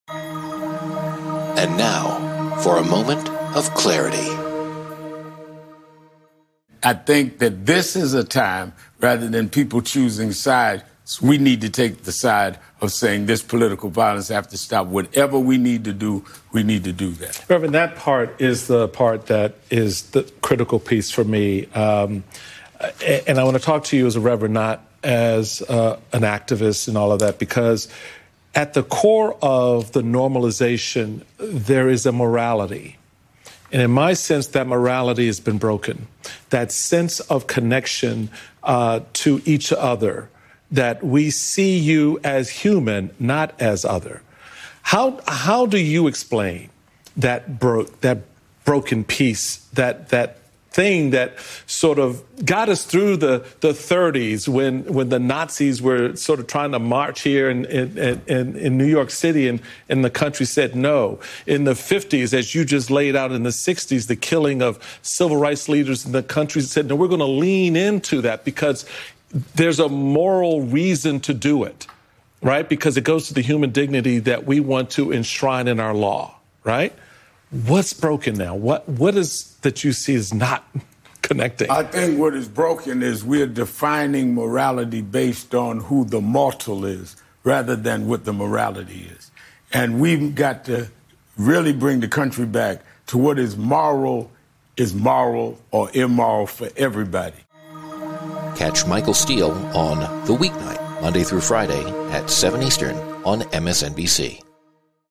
In a powerful conversation, Michael Steele and Reverend Al Sharpton confront the urgent need for a collective stand against political violence.